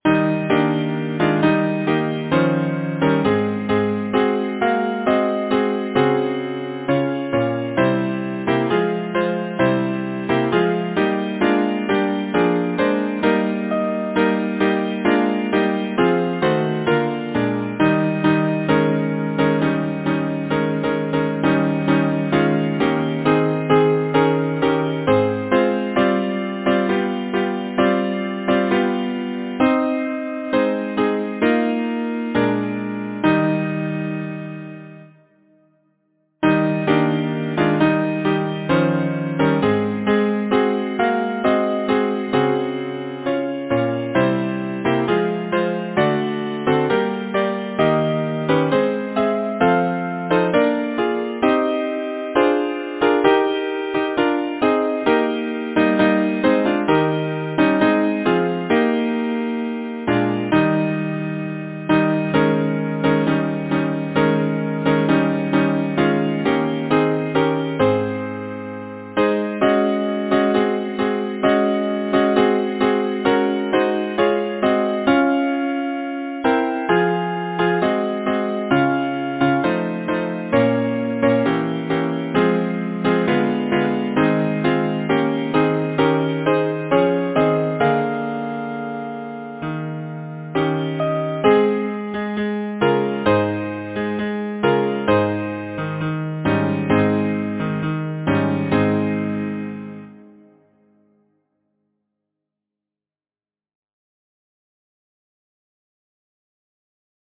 Title: A Sunny Shaft Did I Behold Composer: Charles Harford Lloyd Lyricist: Samuel Taylor Coleridge Number of voices: 4vv Voicing: SATB Genre: Secular, Partsong
Language: English Instruments: A cappella